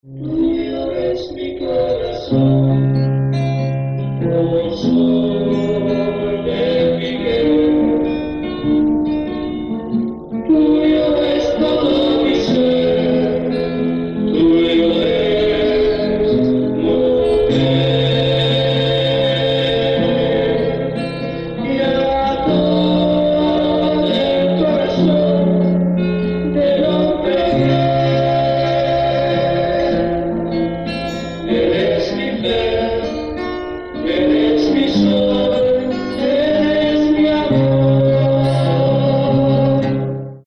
Esecuzione di brani di musica latinoamericana.
a tre voci
registrazione dal vivo